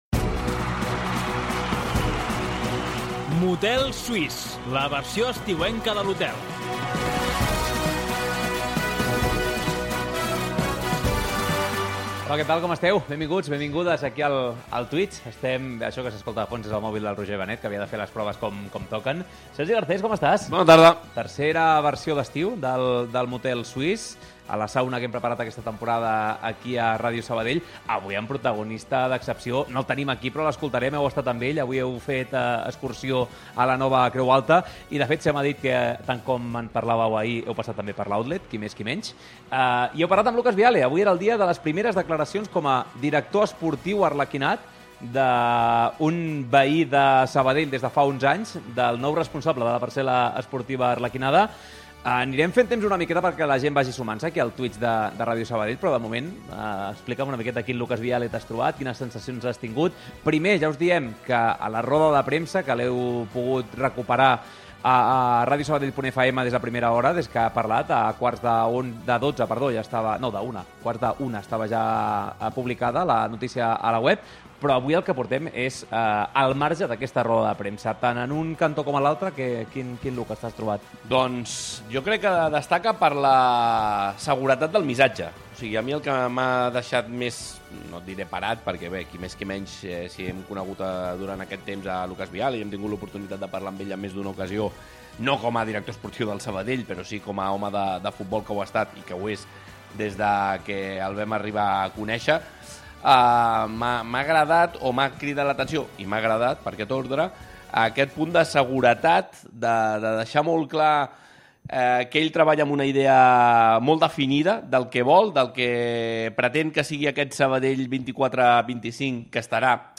MOTEL SUÍS #03: Entrevista